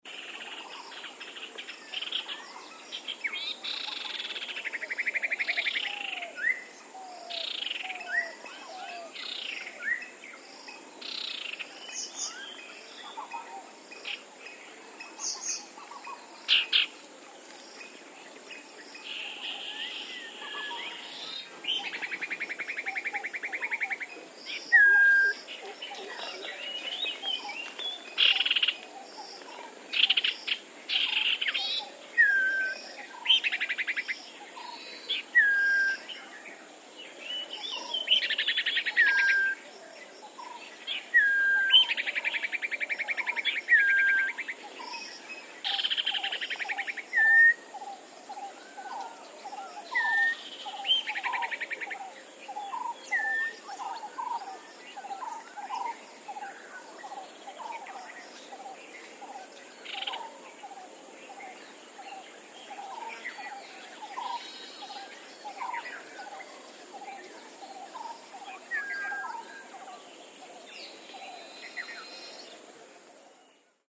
This page lists the animal species which are heard in the foreground or the background in the soundscapes featured on the map.
Sigiriya Sanctuary: recorded at 9.20 am on 27 September 2004
Common Iora, Brown-headed Barbet, Common Myna, Western Spotted Dove, Purple-rumped Sunbird, White-browed Bulbul, White-rumped Shama, Oriental Magpie-robin, and insects.